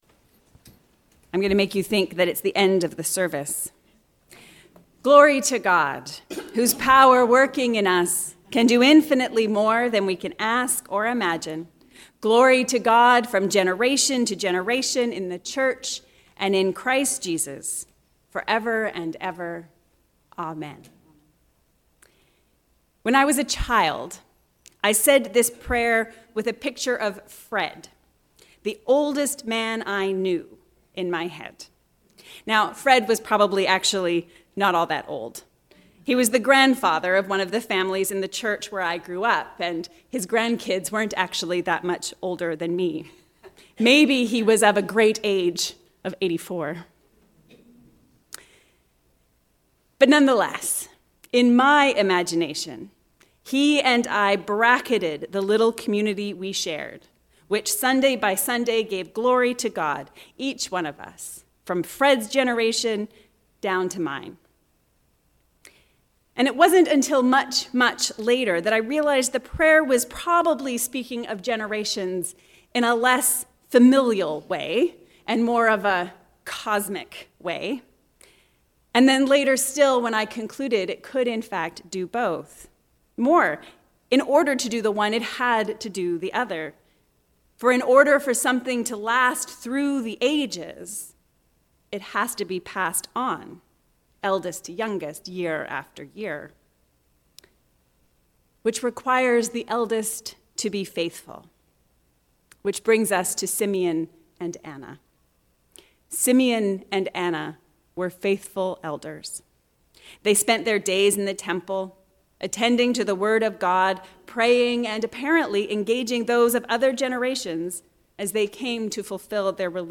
From generation to generation. A sermon for the first Sunday after Christmas.